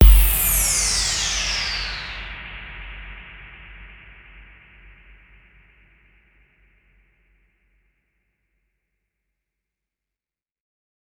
BWB UPGRADE3 FX FALL (10).wav